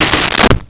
Toilet Seat Fall 03
Toilet Seat Fall 03.wav